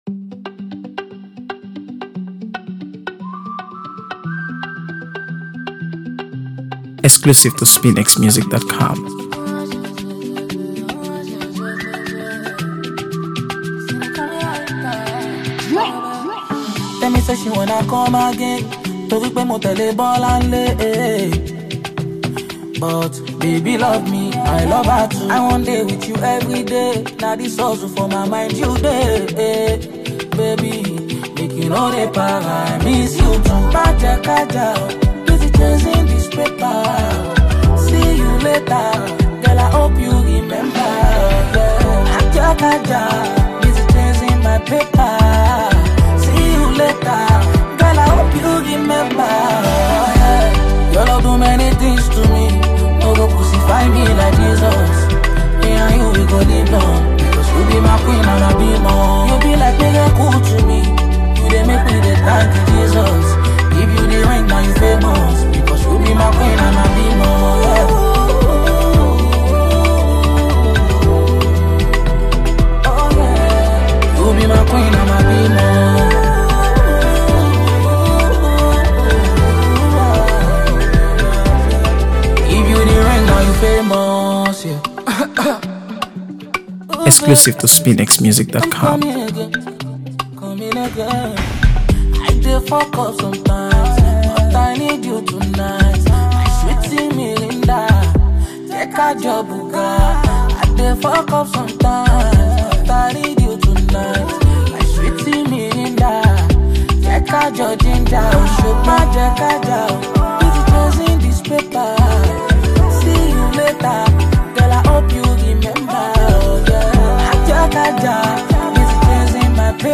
AfroBeats | AfroBeats songs
If you’re a fan of soulful love songs